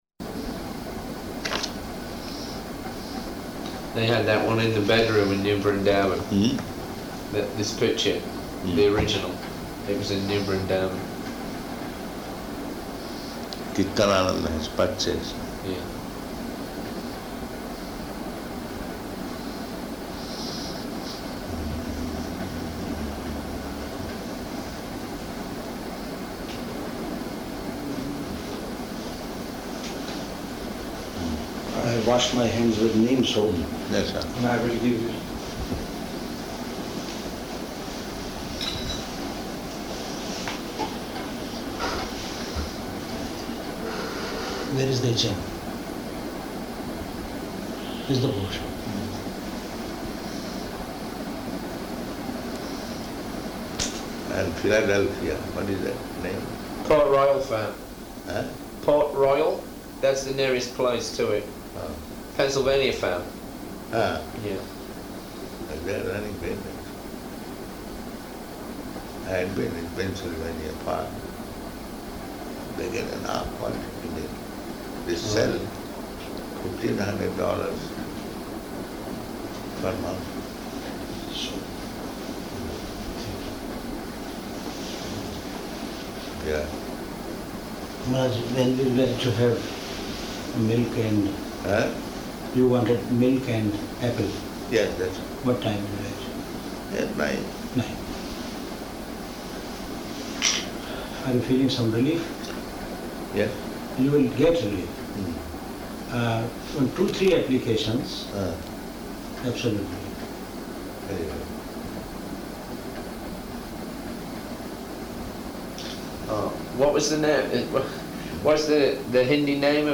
Room Conversation
Location: Aligarh